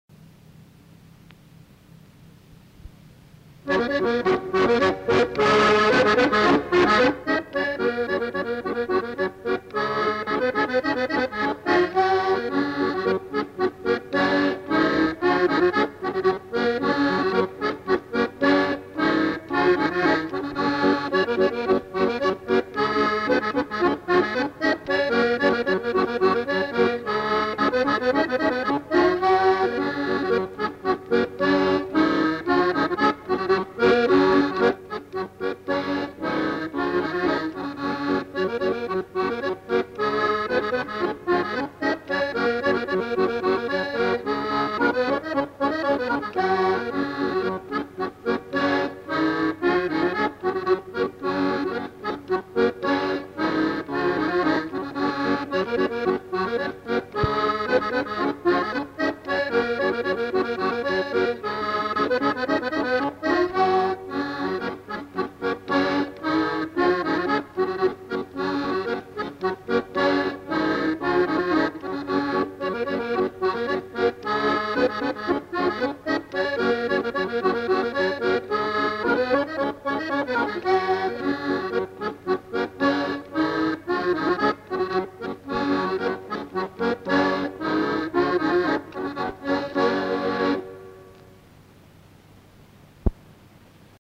Polka
Lieu : Monclar d'Agenais
Genre : morceau instrumental
Instrument de musique : accordéon diatonique
Danse : polka